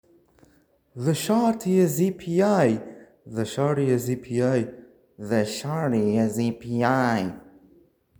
Smiling friends impressions.m4a 📥 (162.07 KB)
Don't ask why I was in an echoed room while recording